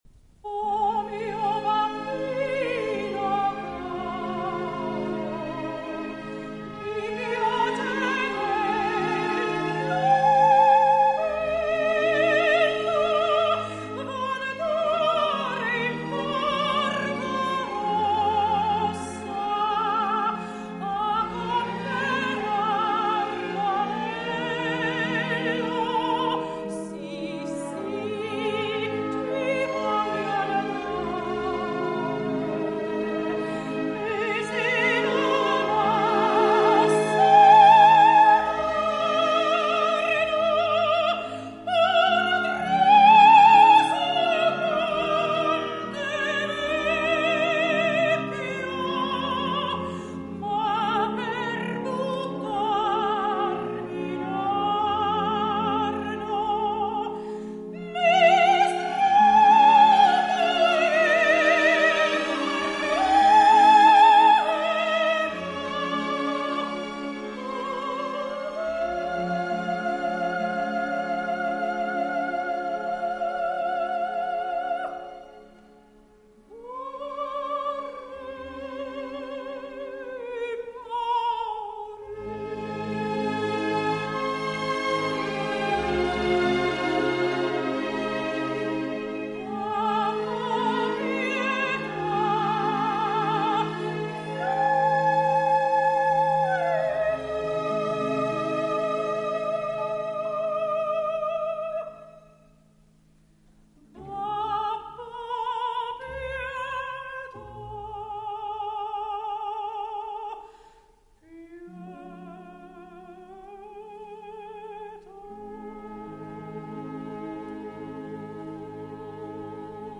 Lauretta [Sopran]